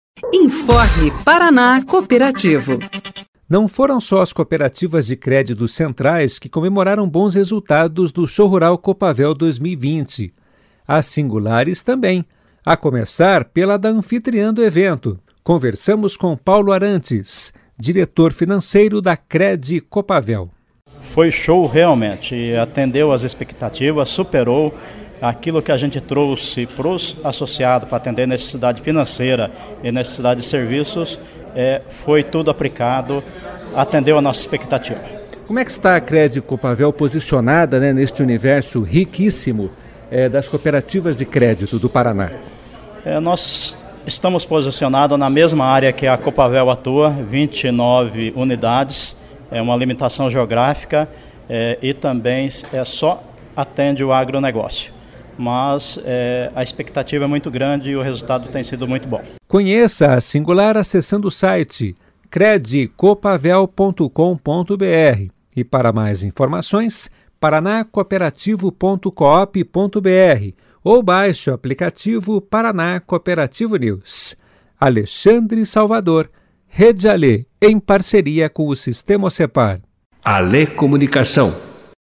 Conversamos com